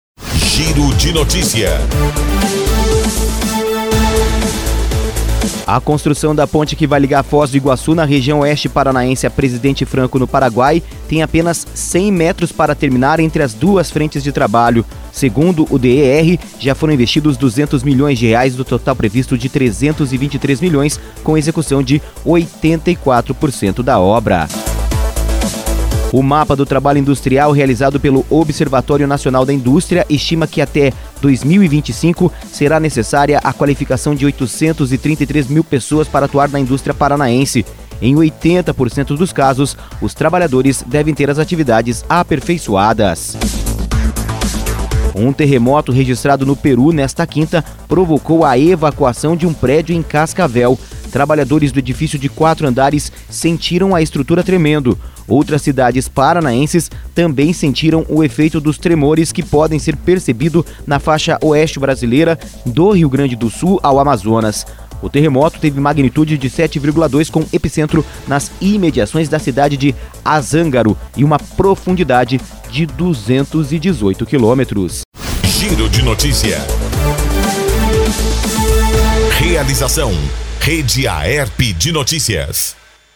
Giro de Notícias – Edição da Manhã